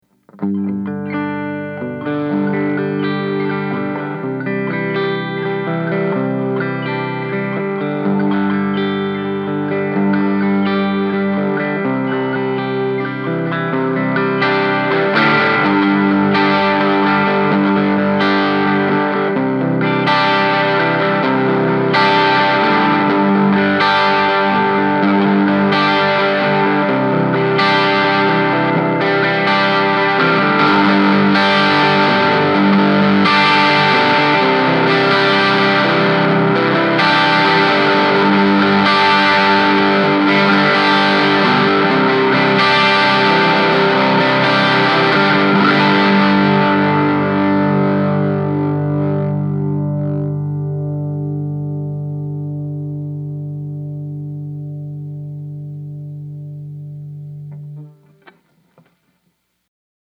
Dynamics
With this first clip, what I wanted to test was how the amp reacted to pick attack. So I set the amp to the edge of breakup.
Then I dig in a little harder to get it past that point, then I engage the boost, which gets the amp into some serious crunch, and loads of sonic content (besides being dirty, with boost engaged, the amp grows some serious balls – even set to bright. I’m playing my R8 Les Paul with both pickups engaged and the volumes are both set to 5.
l34_dynamics.mp3